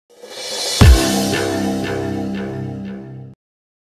payday-2-new-objective-sound-effect.mp3